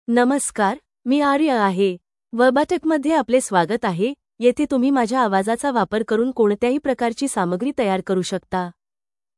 Aria — Female Marathi AI voice
Aria is a female AI voice for Marathi (India).
Voice sample
Listen to Aria's female Marathi voice.
Female